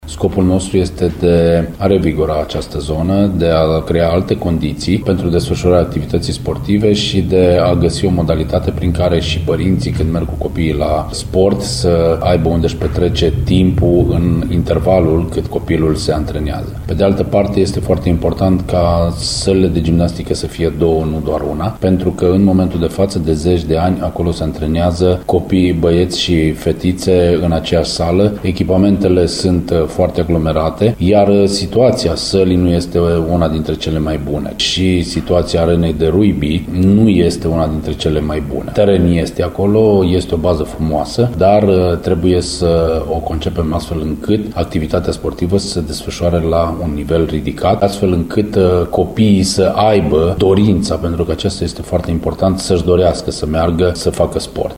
Deși suma pentru realizarea celor doua investiții nu este foarte mare iar bugetul local o poate suporta, conducerea primăriei intenționează sa atraga bani europeni sau de la guvern, cel puțin pentru unul dintre obiective, spune viceprimarul Cosmin Tabără.